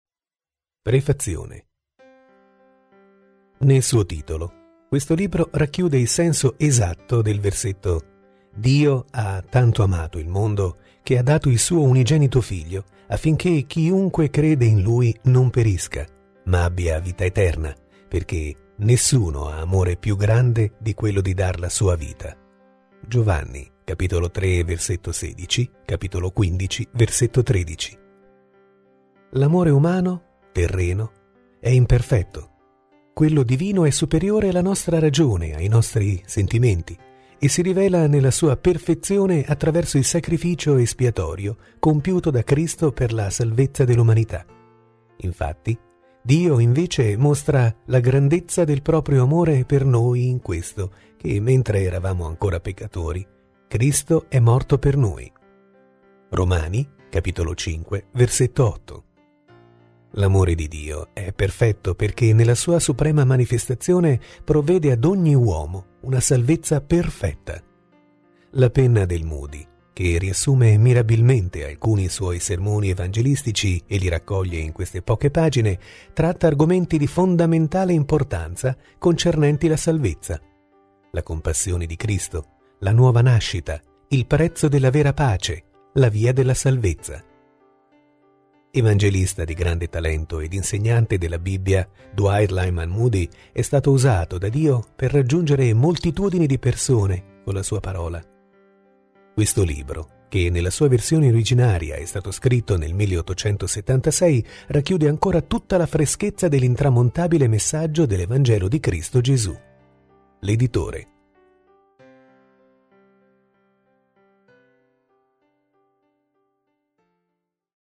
Lettura integrale MP3